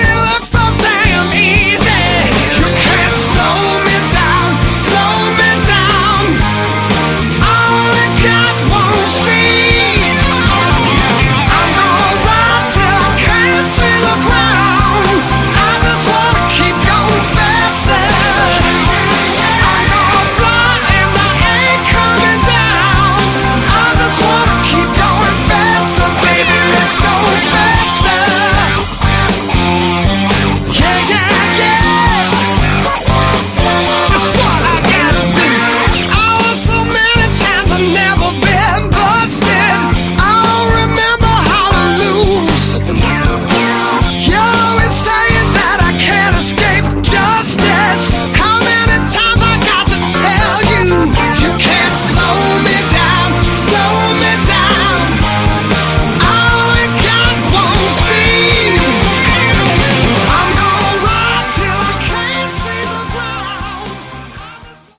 Category: Hard Rock
vocals, guitar, mandolin
keyboards
bass
drums, percussion
additional backing vocals